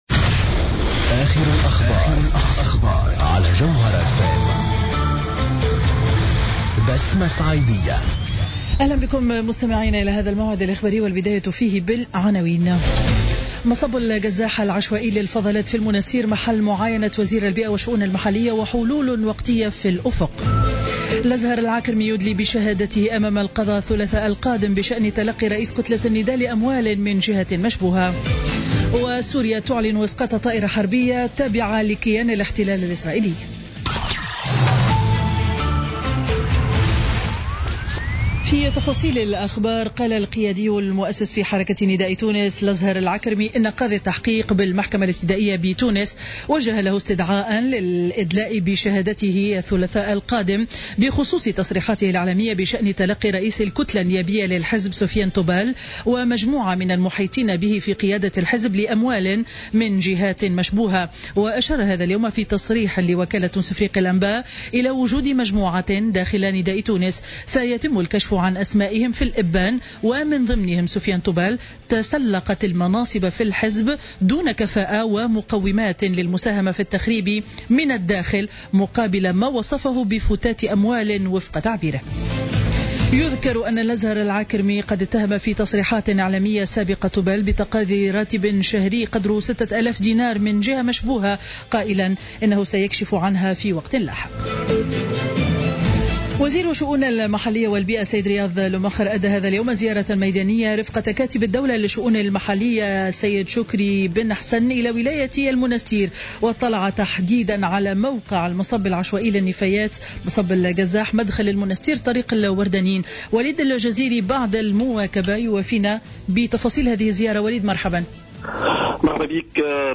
نشرة أخبار منتصف النهار ليوم الجمعة 17 مارس 2017